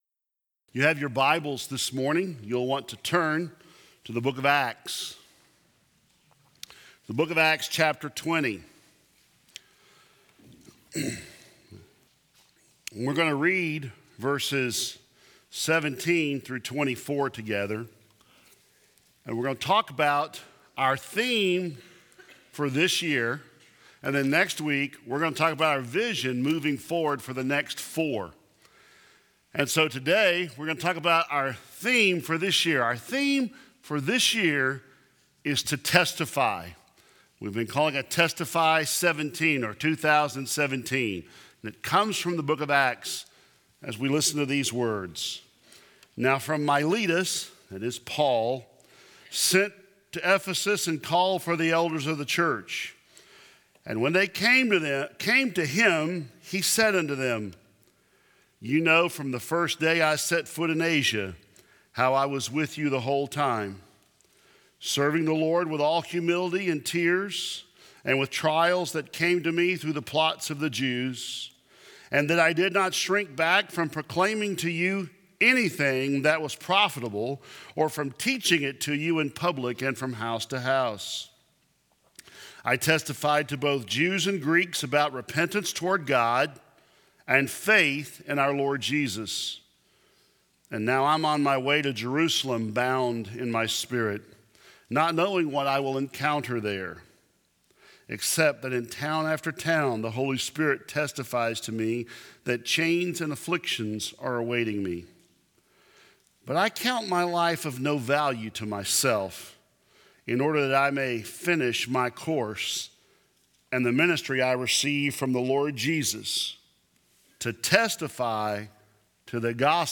2017 Related Share this sermon